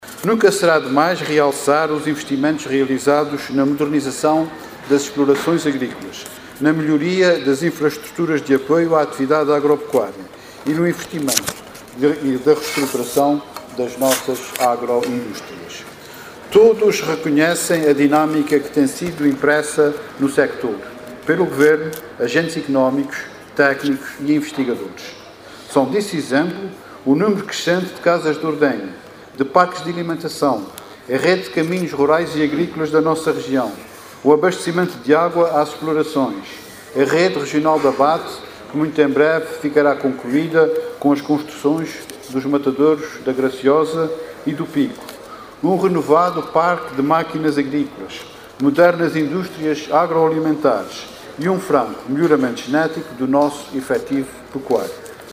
O Secretário Regional dos Recursos Naturais destacou hoje, na inauguração da Feira AGROTER 2014, na Praia da Vitória, os investimentos realizados pelos empresários “na modernização das explorações agrícolas, na melhoria das infraestruturas de apoio à atividade agropecuária e no investimento na reestruturação das agroindústrias”.